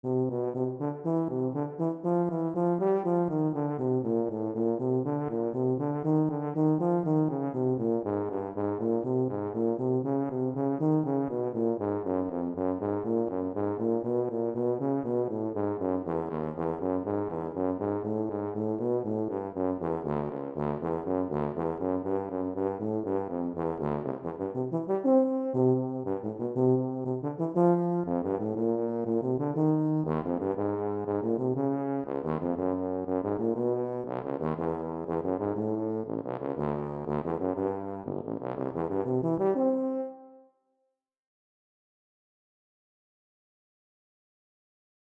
Here is a little warm-up exercise I composed for tuba using the FINALE composition program.
tuba-m3.mp3